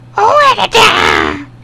RATTATA.mp3